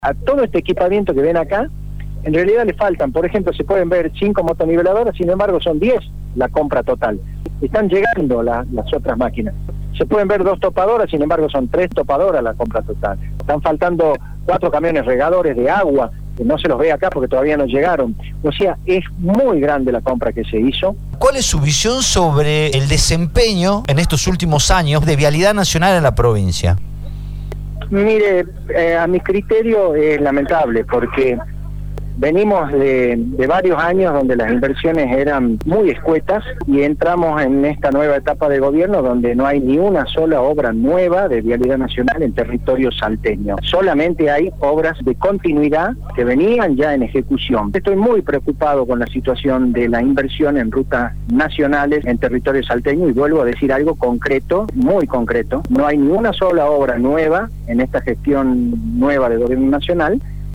El Director de Vialidad de la Provincia, Gerardo Villalba, en diálogo con Radio Dínamo, se refirió al equipamiento recibido con fondos del Plan Bicentenario  y dijo que el rol de Vialidad Nacional en la provincia es lamentable, además sostuvo que no hay ni una sola obra nueva en la gestión del gobierno nacional”.